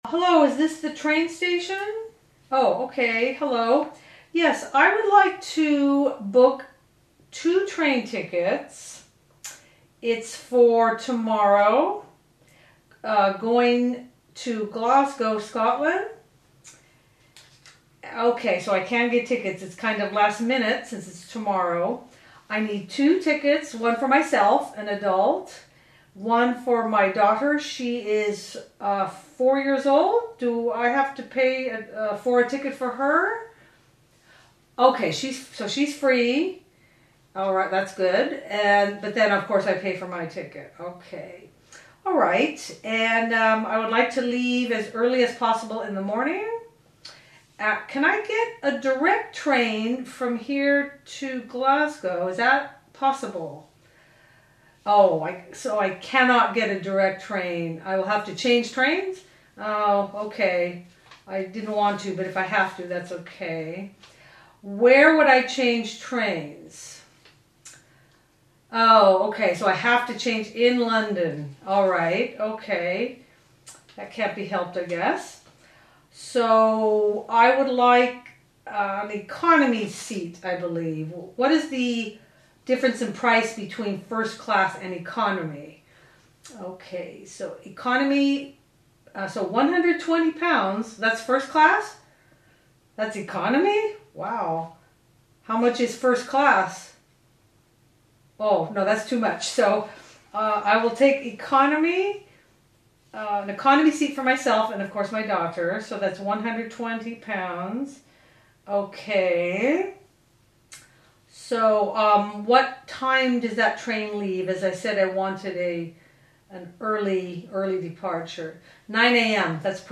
Phone Calls in English audiokniha
Ukázka z knihy
• InterpretRôzni Interpreti